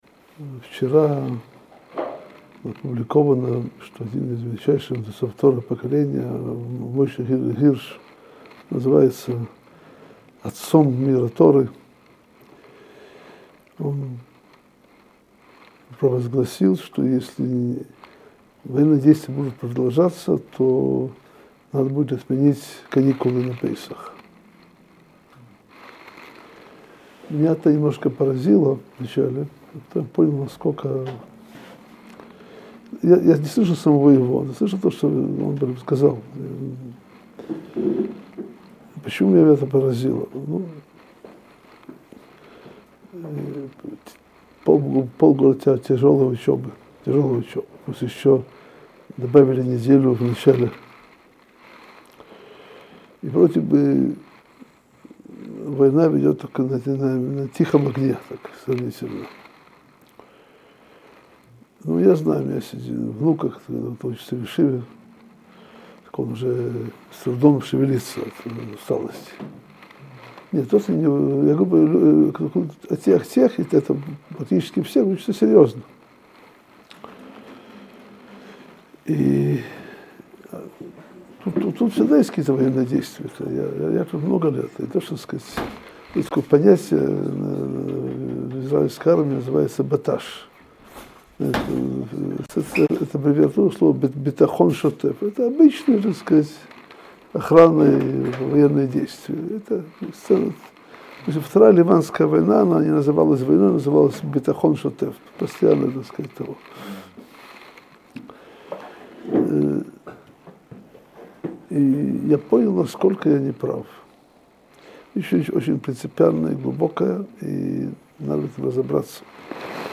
Содержание урока: Нужно ли отменять пасхальные каникулы из-за войны? Сколько солдат погибло за время войны в Газе?